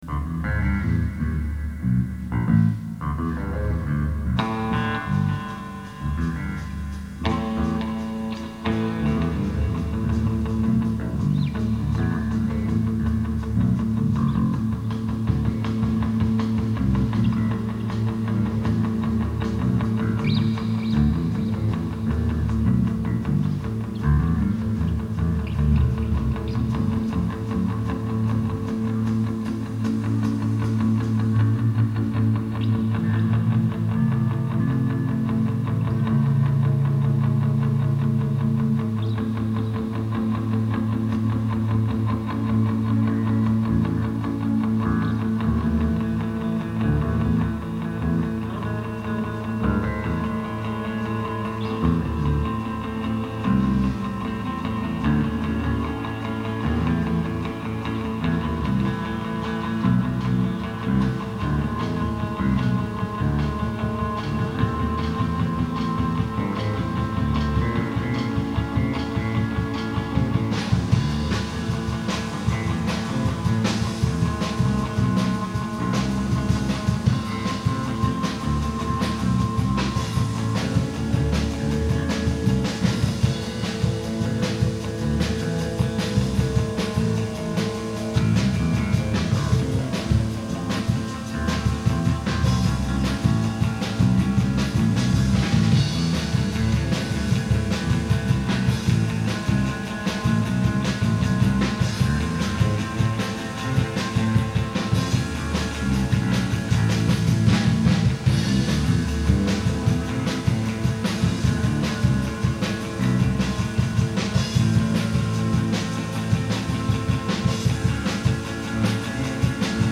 En esta sección GRAVELAND encontraréis periódicamente grabaciones en directo, en baja calidad (grabados en cassete con un micro colgado de un palo), con comentarios banales pisando inicios y finales de algunos cortes, discusiones sobre paridas insólitas, batallas de volúmenes, y bromas y referencias personales que a veces ni siquiera entendemos nosotros al hacer la reaudición.
El bajo arranca con una frase improvisada que después me cuesta reproducir. Puesto que la guitarra empieza a tocar otra cosa y la batería la sigue, me las he de ingeniar para conseguir que entre como sea mi línea (es que somos así de tozudos!). Hacia el minuto 2:25 introducimos un cambio que al final acabamos llenando con voces a coro.